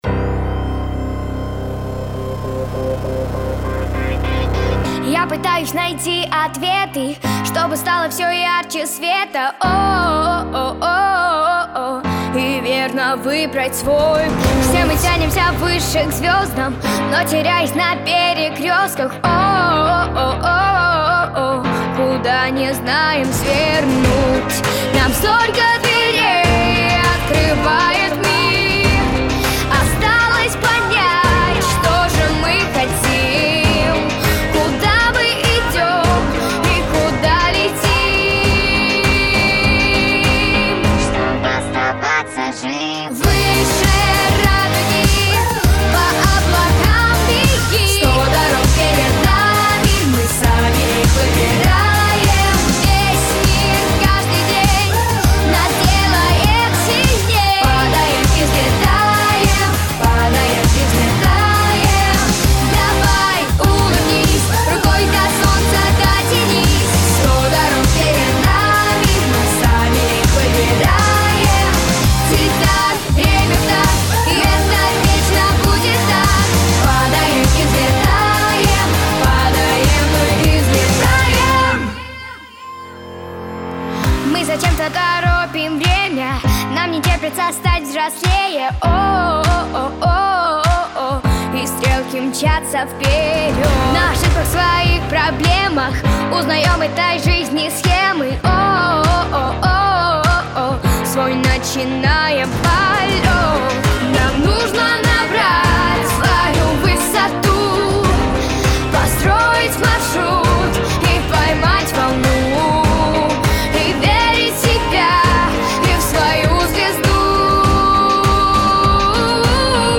Детские Детские песни